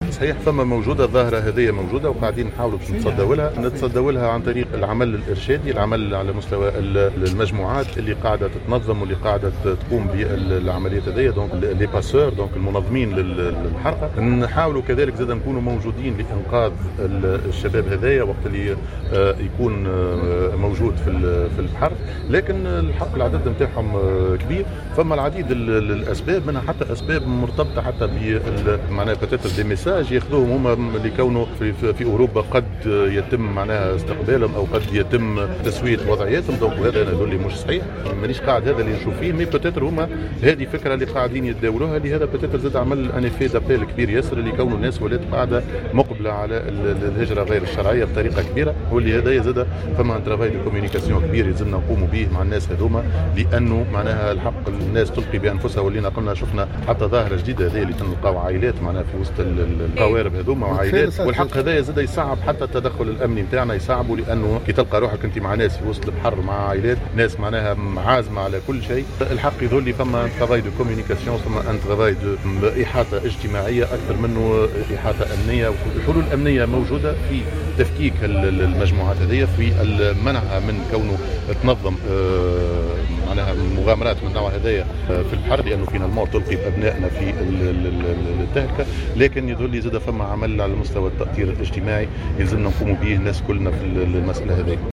وأضاف خلال اشرافه مساء اليوم الخميس على الدورة الثالثة لحفل تخرج رقباء الحرس الوطني لسنة 2019 -2020 بالمدرسة الوطنية للتكوين المستمر للحرس الوطني بالشبيكة من ولاية القيروان، انه لا وجود لمثل هذا الاجراء في تلك الدول وانه يجب العمل على معالجة هذه الظاهرة، اتصاليا وعبر الاحاطة والتاطير الاجتماعي وذلك الى جانب الجهد الامني في التصدي للمجموعات التي تنظم هذه العمليات والعمل على تفكيكها .